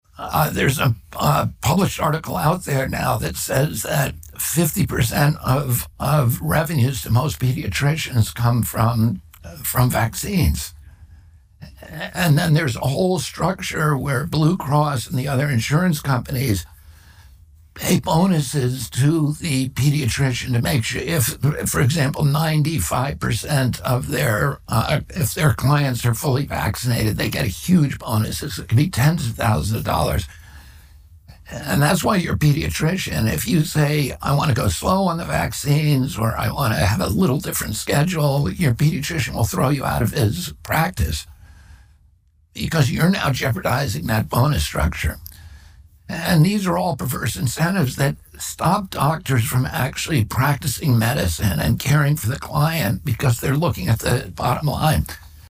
RFK Jr. Interview:
RFK Jr. did an interview with Tucker Carlson earlier this week where the two discussed vaccines.